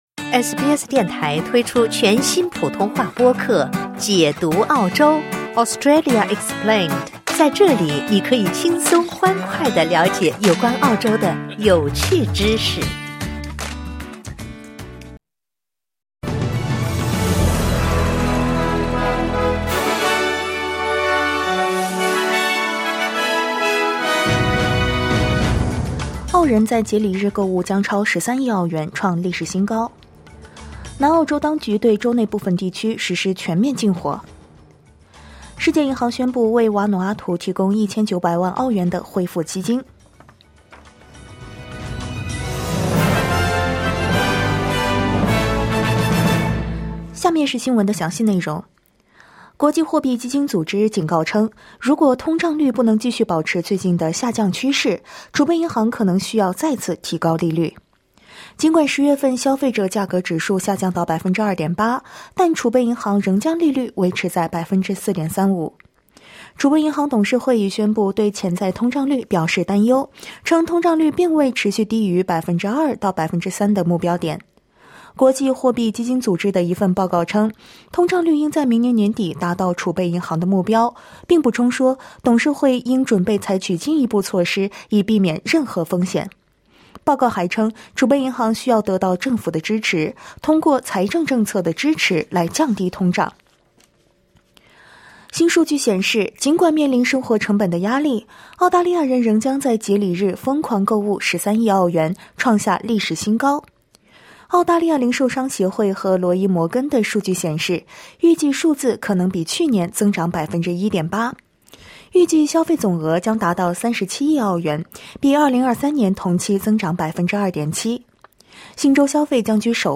SBS早新闻（2024年12月25日）